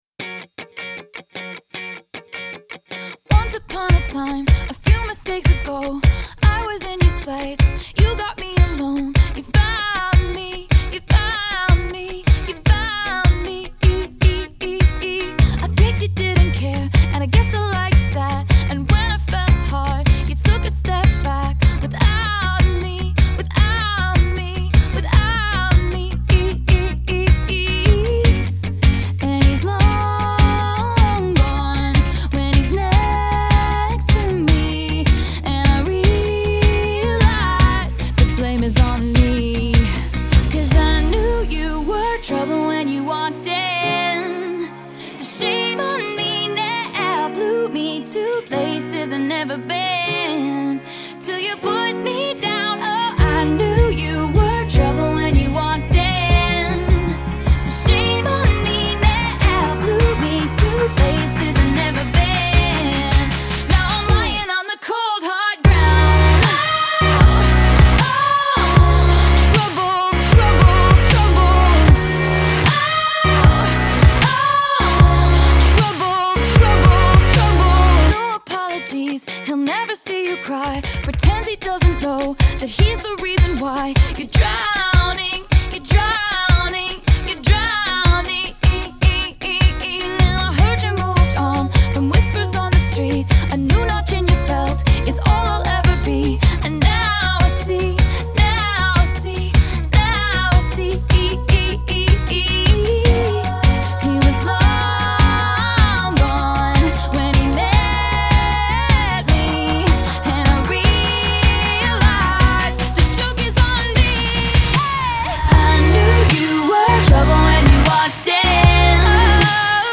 jaunty pop verses